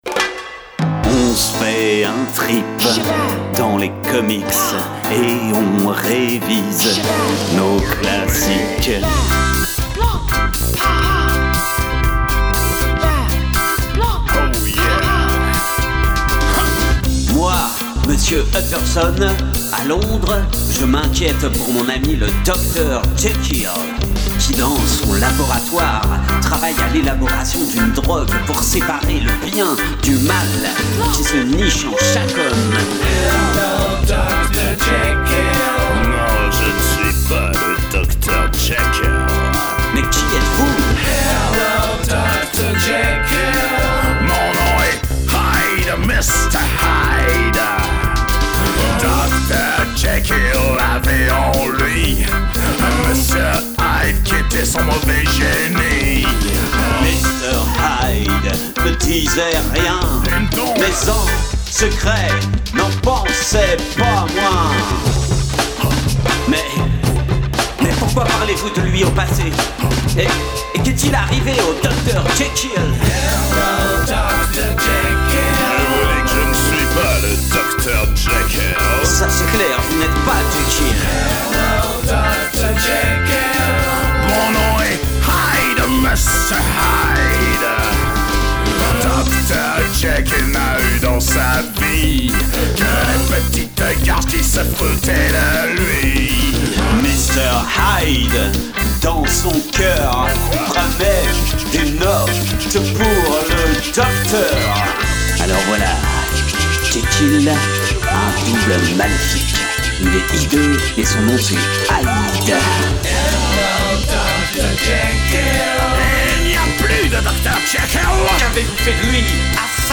# duo
rallongée avec une intro originale